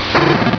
Cri de Marcacrin dans Pokémon Rubis et Saphir.